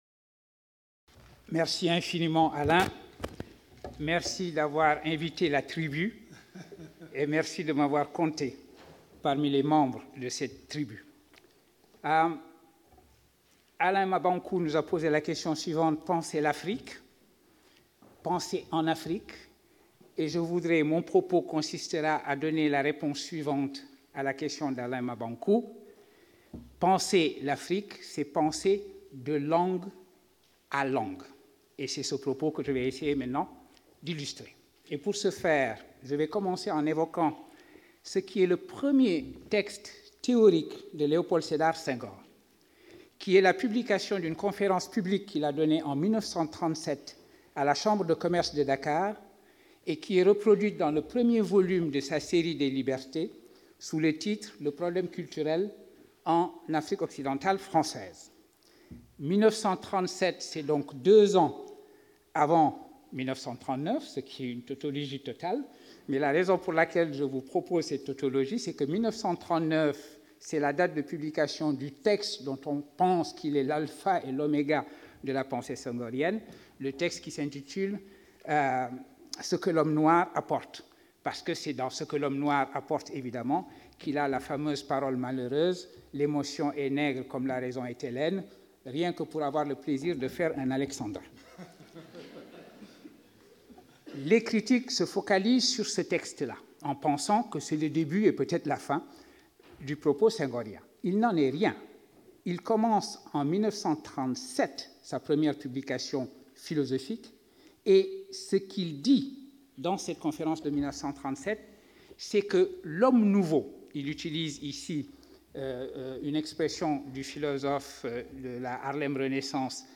Speaker(s) Souleymane Bachir Diagne Philosopher, Columbia University
Symposium